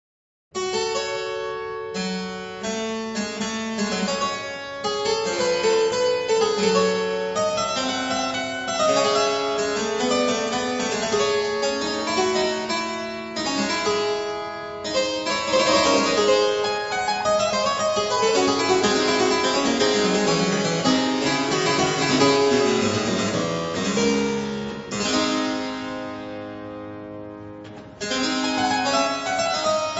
cembalo